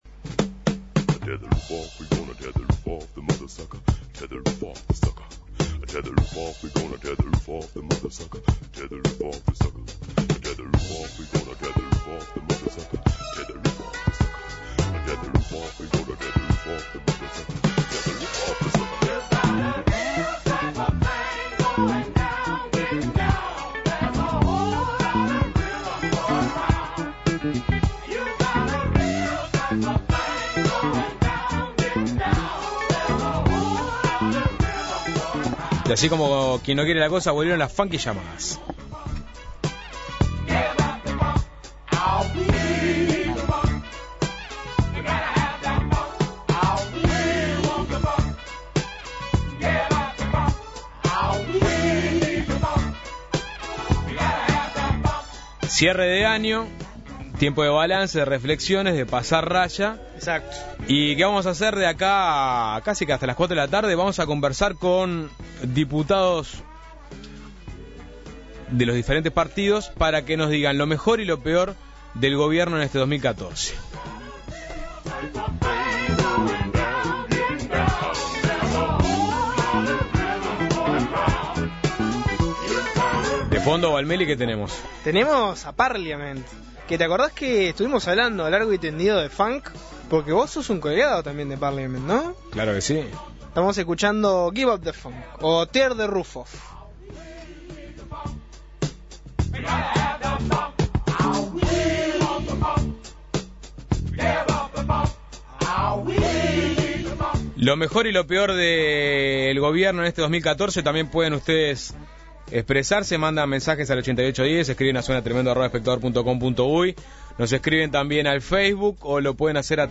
Volvieron las funkyllamadas. En un ping pong de ida y vuelta con diputados oficialistas y opositores de primera línea, Suena Tremendo consultó por lo mejor y lo peor del gobierno en 2013.